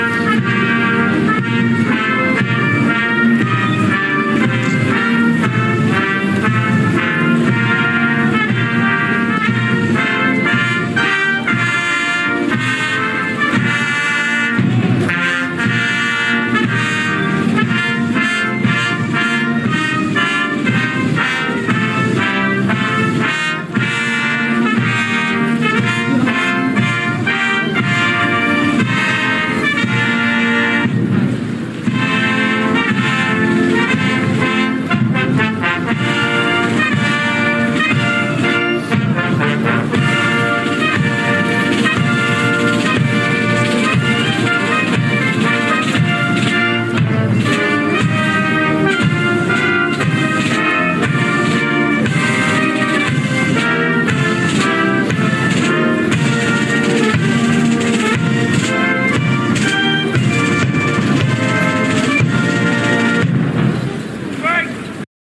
Some of the Marching Bands who performed for Her Grace